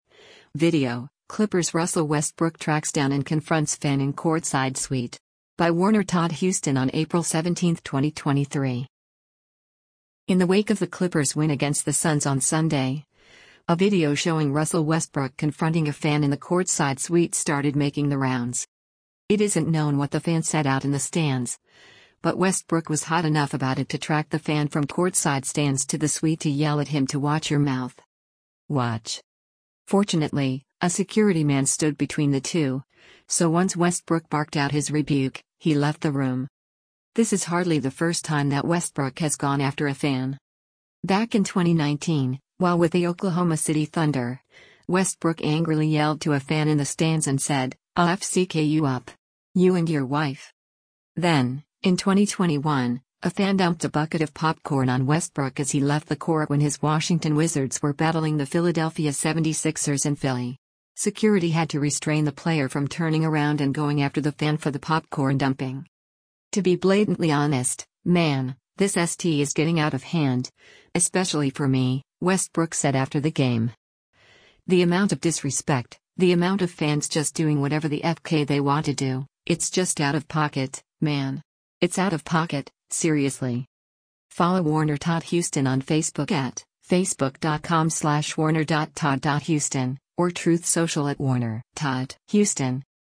It isn’t known what the fan said out in the stands, but Westbrook was hot enough about it to track the fan from courtside stands to the suite to yell at him to “watch your mouth.”
Fortunately, a security man stood between the two, so once Westbrook barked out his rebuke, he left the room.